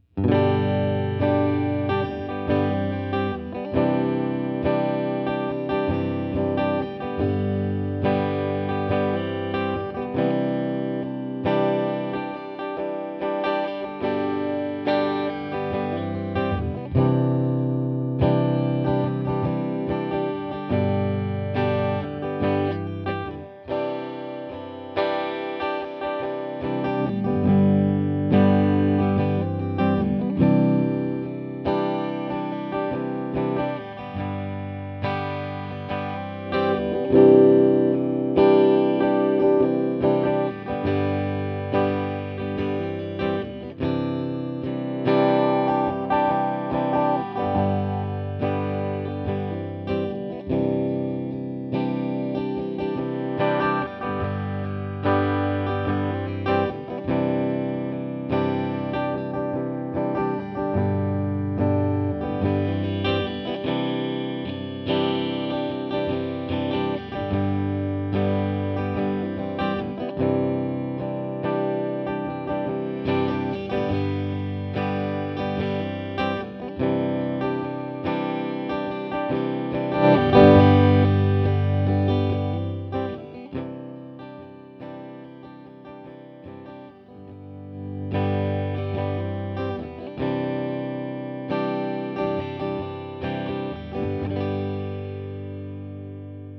Pedal WALDMAN para Guitarra, Pro Equalizer Mod. PEQ-1, Linha Stage FX
Pedal-PEQ-1.wav